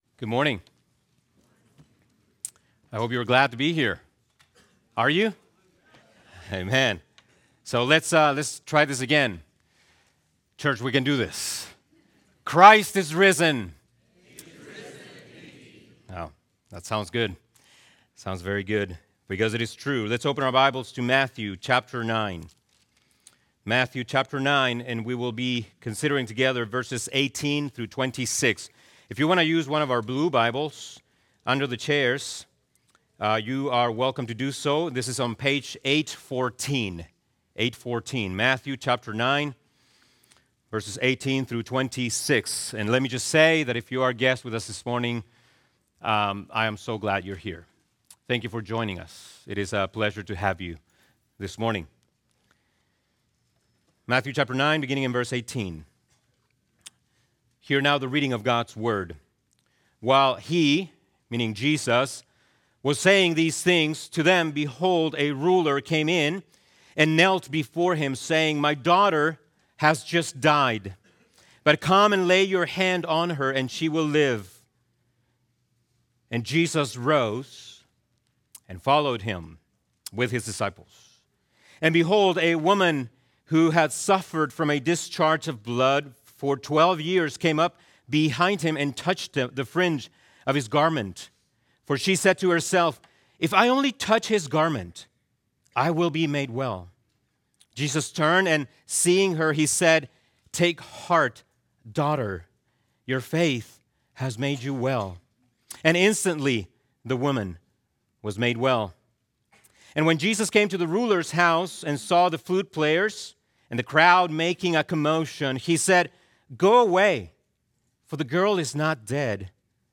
Sermons
Sunday Sermons